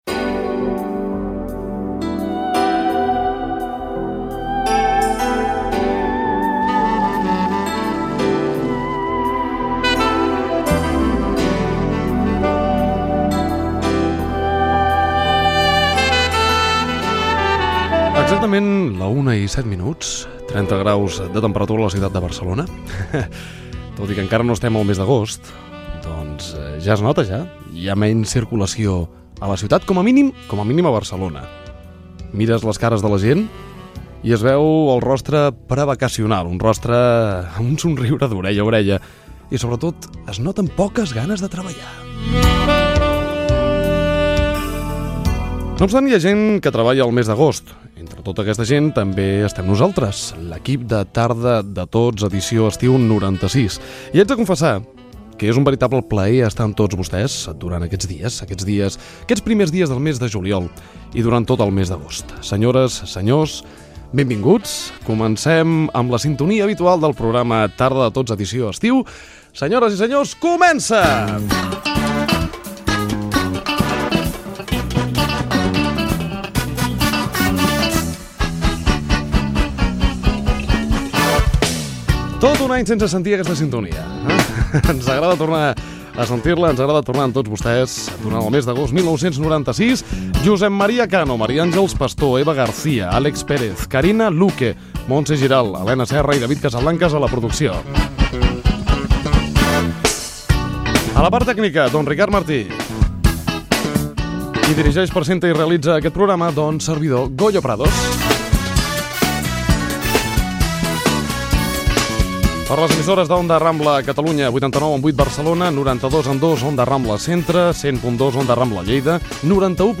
Hora, temperatura, presentació, sintonia del programa, equip, identificació de les emissores i freqüències, sumari del programa
Entreteniment
FM